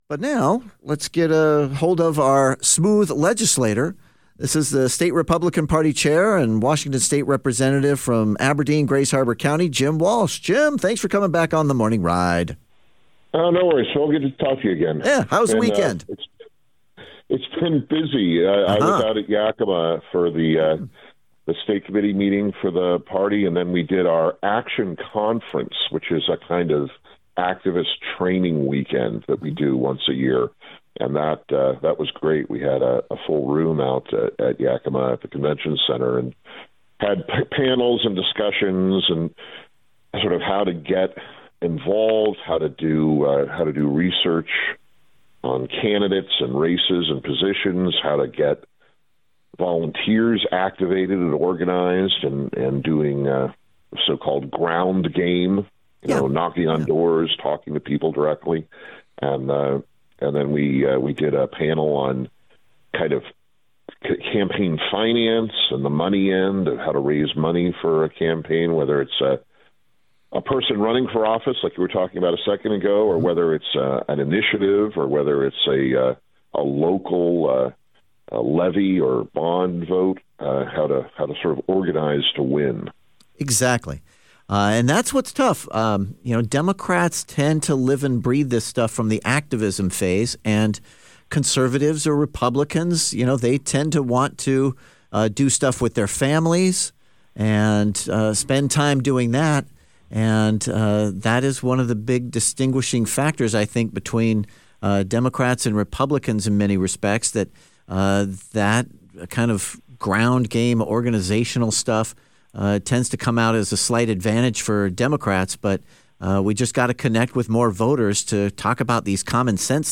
Jim Walsh Talks VP Vance Briefing and Tariff Tensions on KVI’s The Morning Ride